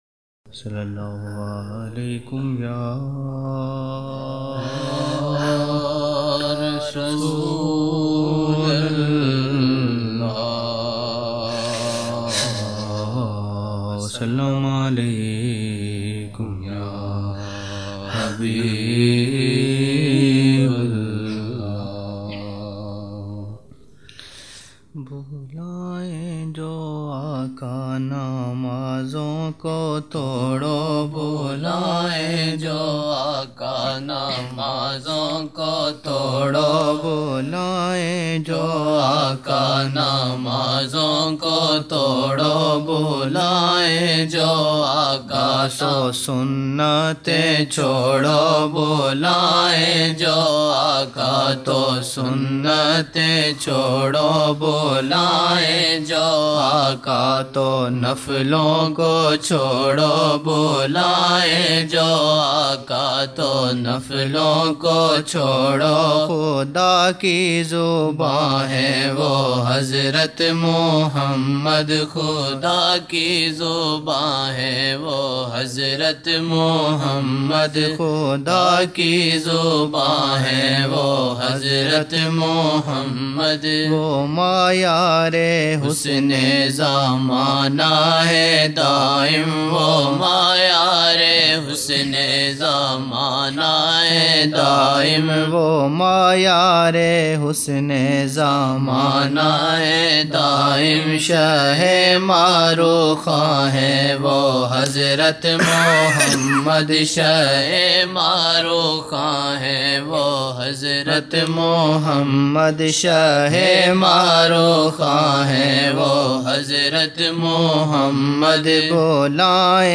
14 November 1999 - Maghrib mehfil (6 Shaban 1420)